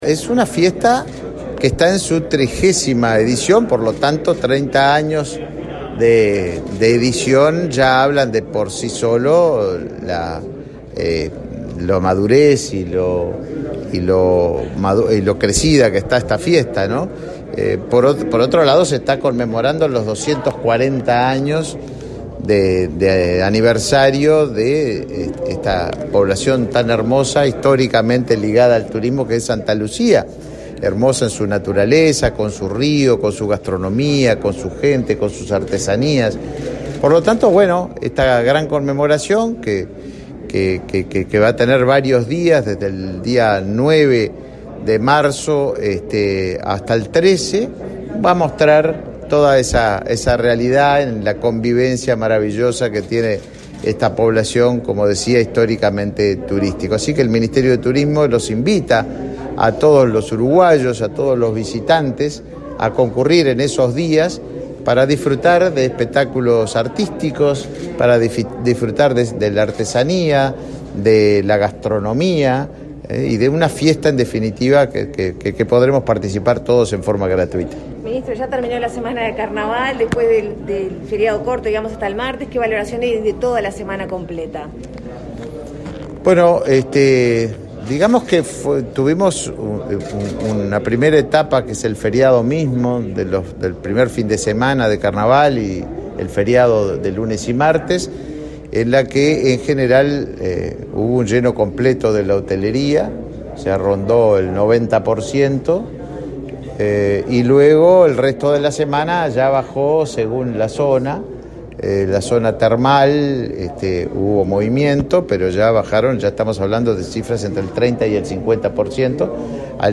Declaraciones a la prensa del ministro de Turismo, Tabaré Viera
Declaraciones a la prensa del ministro de Turismo, Tabaré Viera 07/03/2022 Compartir Facebook X Copiar enlace WhatsApp LinkedIn El ministro de Turismo, Tabaré Viera, dialogó con la prensa, luego de participar de la conferencia por los 30 años de la Fiesta de la Cerveza de Santa Lucía, Canelones, este lunes 7 en la sede ministerial.